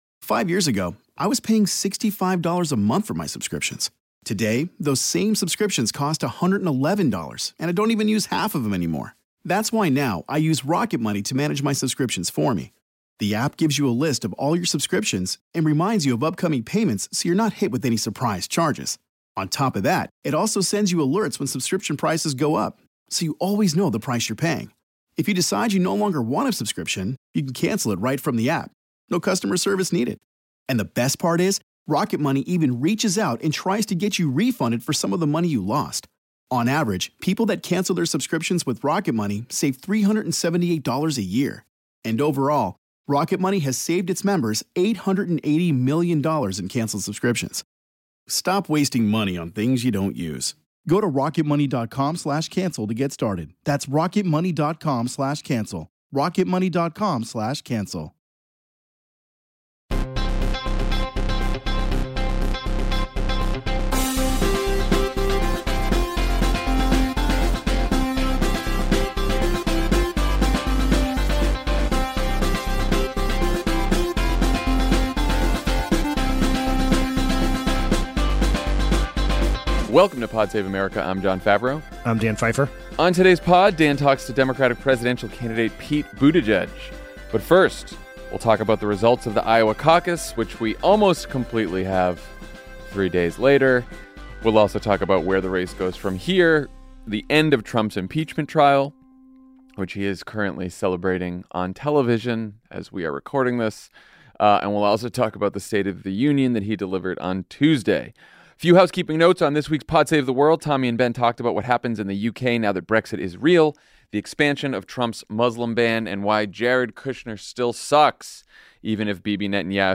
Iowa is still too close to call as the candidates try to break through in New Hampshire, Mitt Romney votes to convict as Trump is acquitted, and the President’s State of the Union should be a wake up call for Democrats. Then Democratic presidential candidate Pete Buttigieg talks to Dan about his post-Iowa strategy.